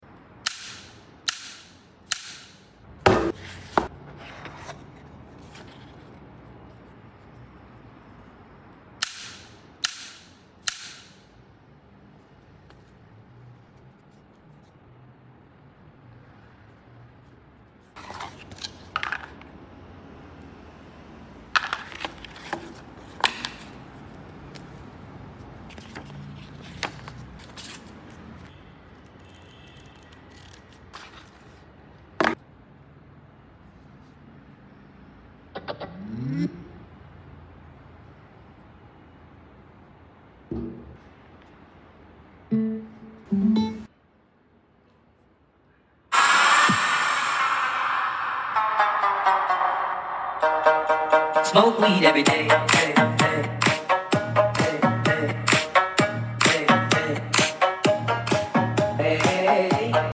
Unboxing JBLClip 5 portable speaker sound effects free download
Unboxing JBLClip 5 portable speaker and sound testing